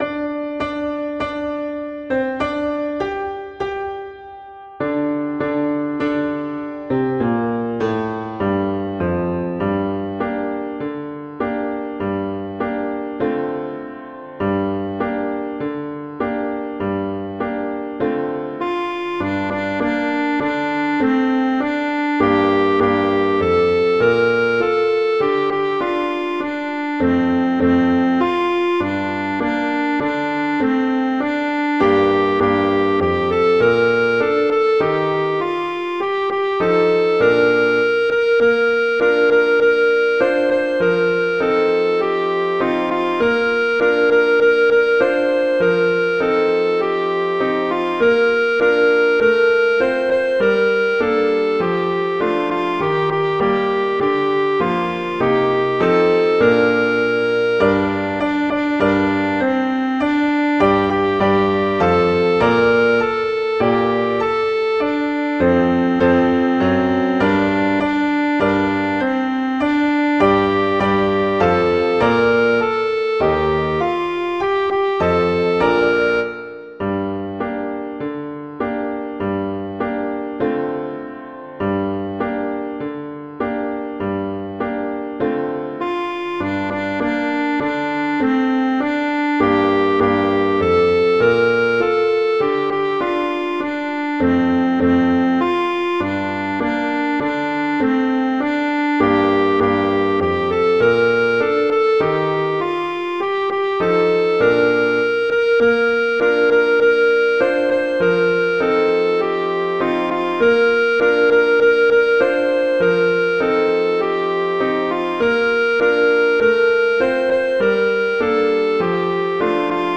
arrangements for clarinet and piano
traditional, irish, children
♩=60-120 BPM